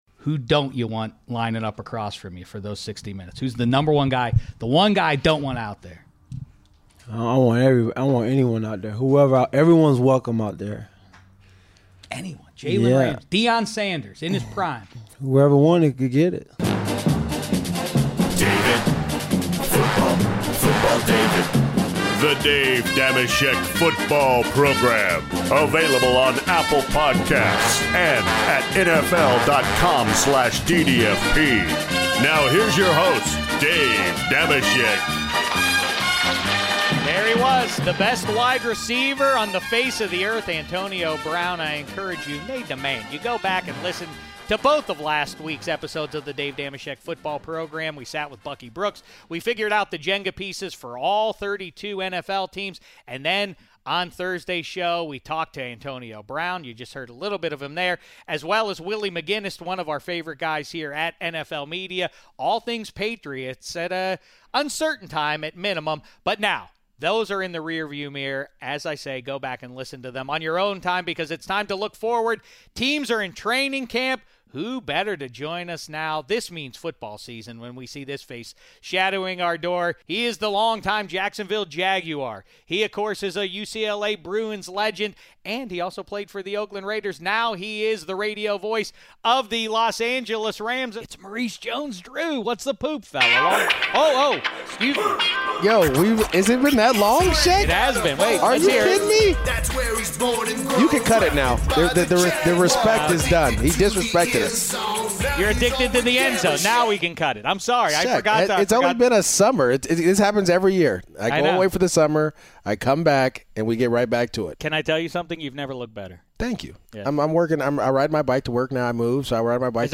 Dave Dameshek is joined in Studio 66 by our old pal Maurice Jones-Drew, who makes his return after taking the summer off.